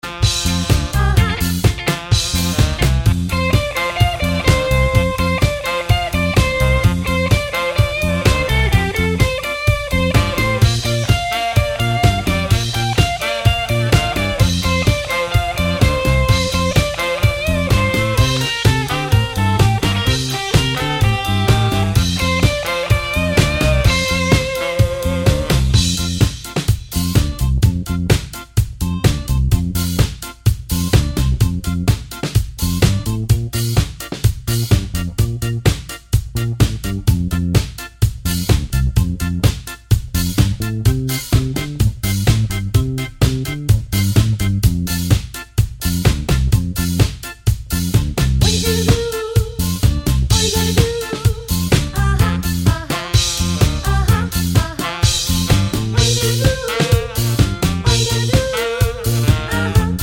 no sax solo Ska 2:57 Buy £1.50